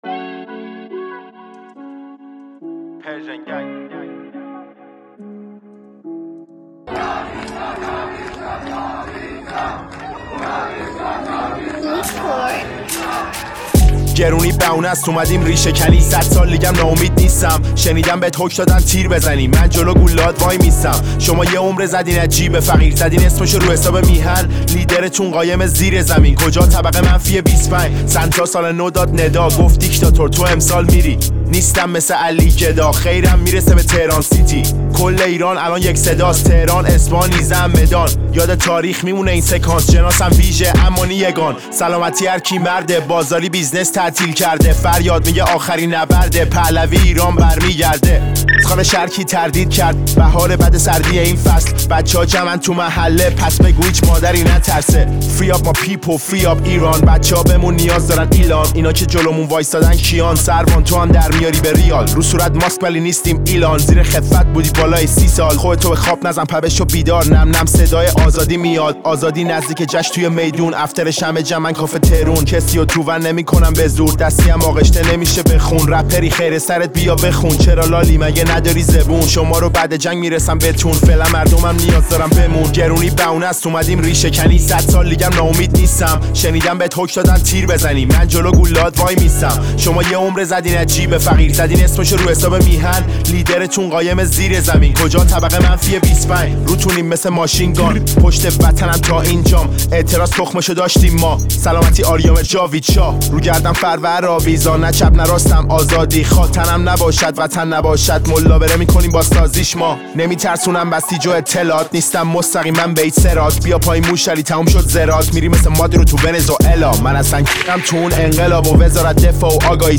اهنگ رپ